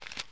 SFX_papel2.wav